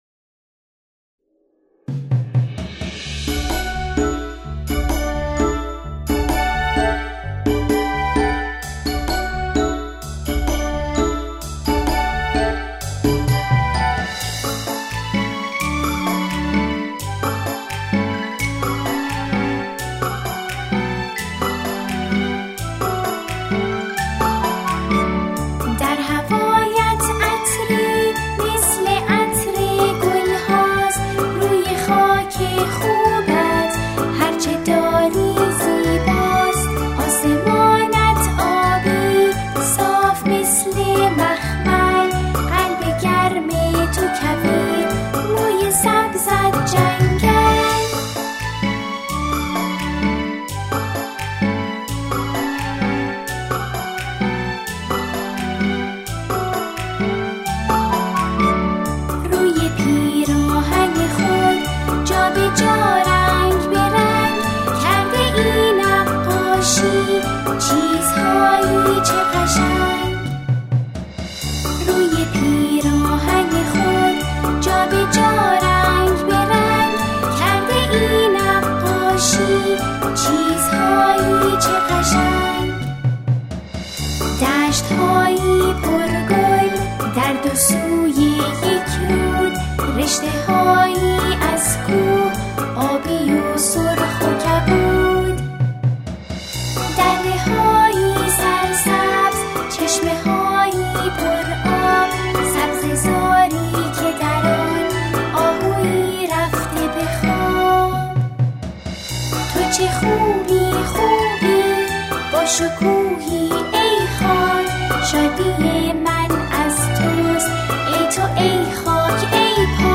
در این قطعه شعری درباره ایران همخوانی می‌شود.